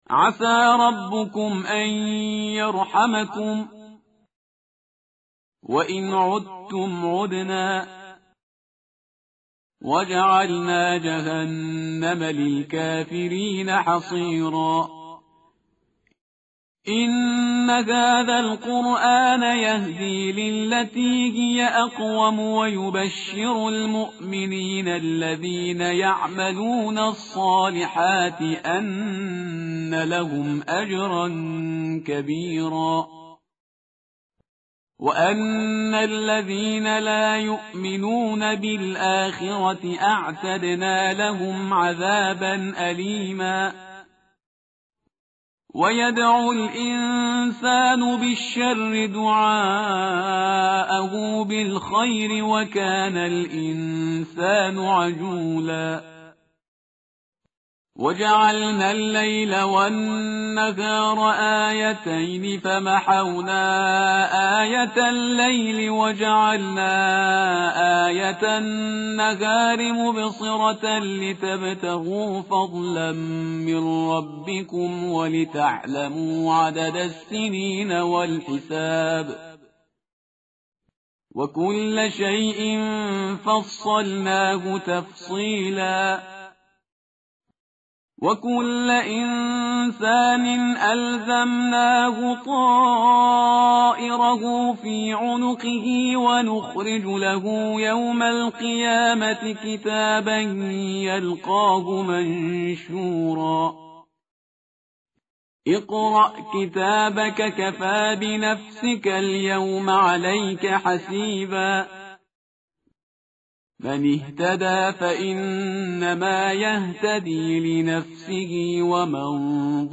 tartil_parhizgar_page_283.mp3